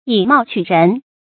注音：ㄧˇ ㄇㄠˋ ㄑㄩˇ ㄖㄣˊ
以貌取人的讀法